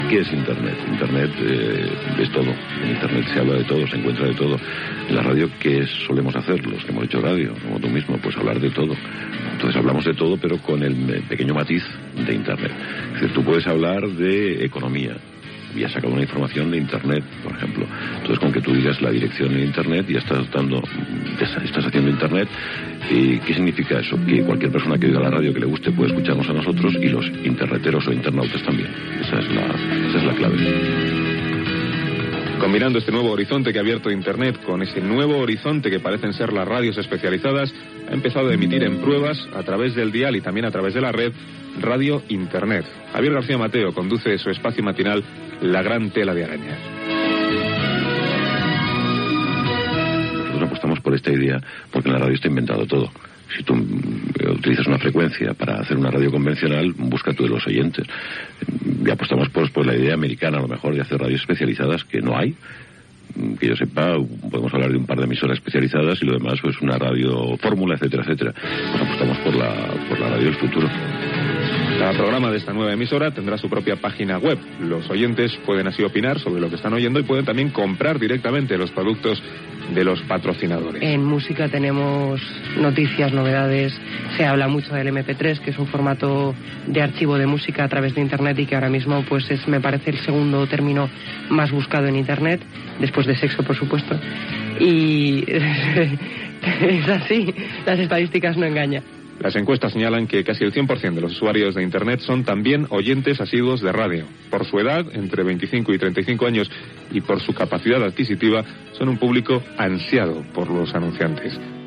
Espai fet des de la Facultat de Periodisme de la Univerdidad Complutense de Madrid. Com serà la ràdio dels propers 75 anys.